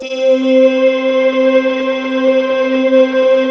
Index of /90_sSampleCDs/USB Soundscan vol.28 - Choir Acoustic & Synth [AKAI] 1CD/Partition C/16-NIMBUSSE